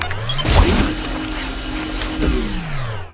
battlesuit_remove.ogg